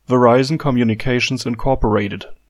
listen (help·info)) (/vəˈraɪzən/ və-RY-zən) is an American multinational telecommunications conglomerate and a corporate component of the Dow Jones Industrial Average.[10] The company is based at 1095 Avenue of the Americas in Midtown Manhattan, New York City,[4] but is incorporated in Delaware.